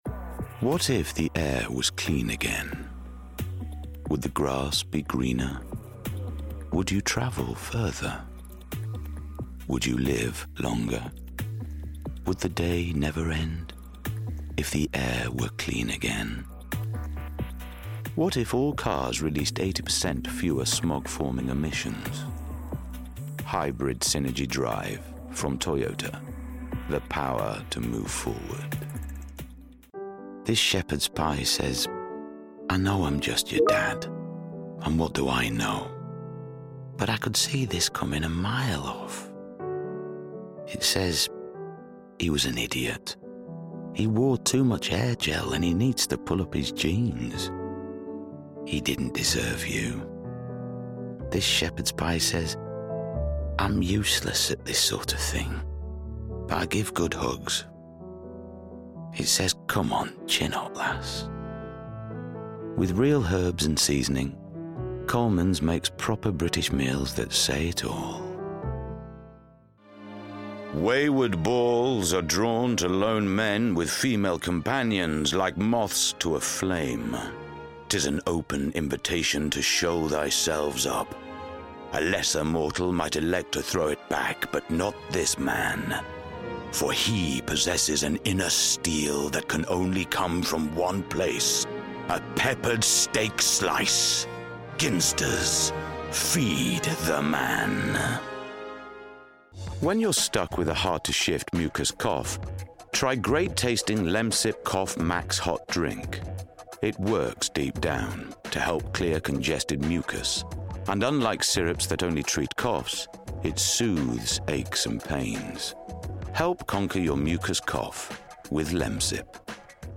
Yorkshire
Male
Deep
Dry
Gravelly
COMMERCIAL REEL